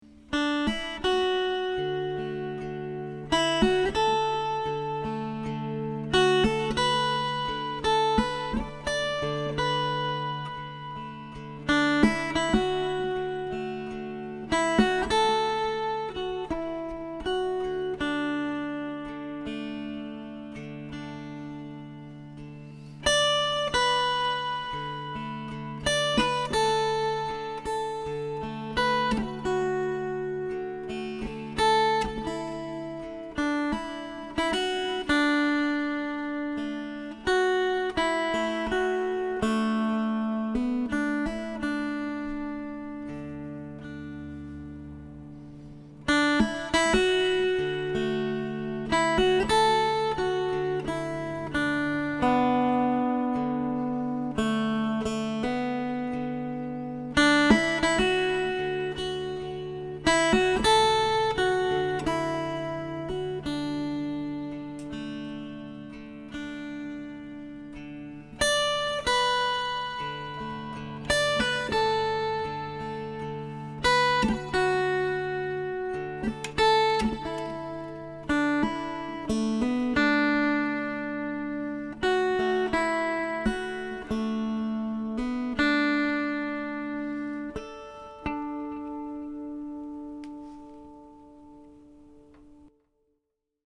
Pentatonic Tune No. 1 - The Relaxing Sounds of the Dulcimer
This one was the first successful original tune in the major pentatonic, using only the notes D, E, F#, A, and B. I am currently up to four of these tunes……I wonder if that means I’m on a roll???